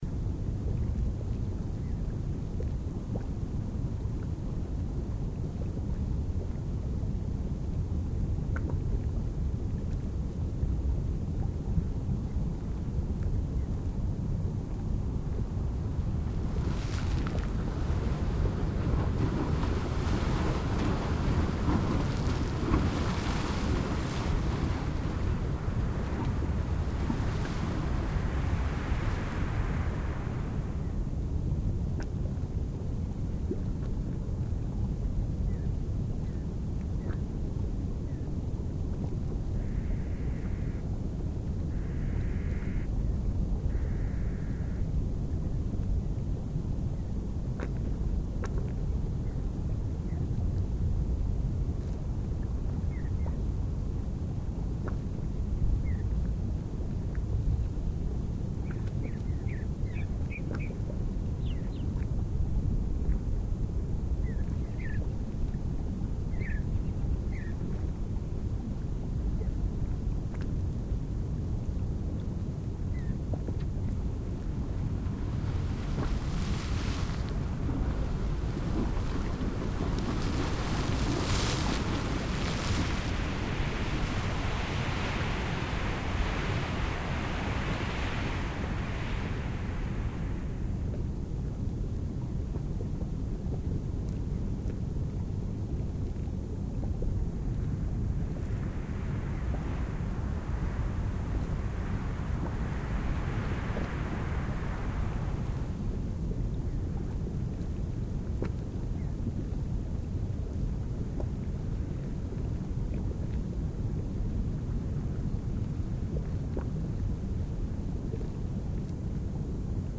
sulphur_springs.ogg